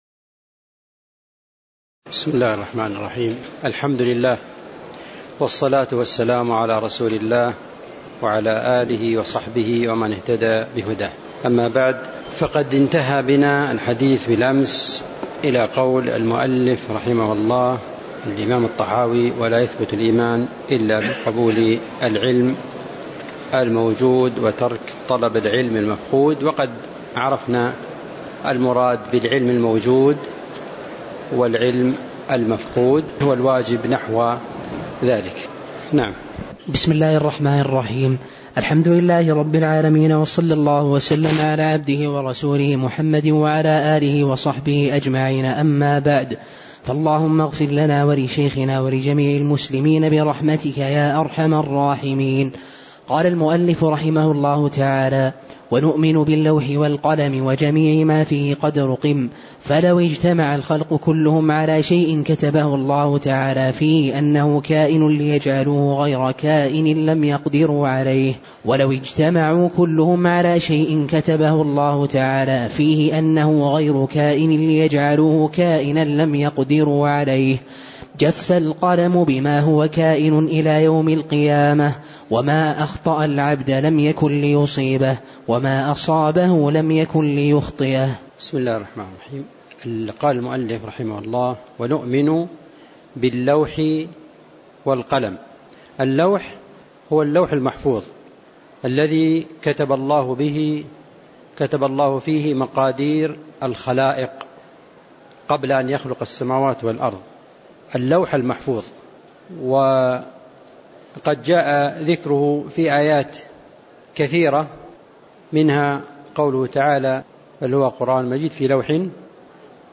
تاريخ النشر ١٢ شوال ١٤٣٩ هـ المكان: المسجد النبوي الشيخ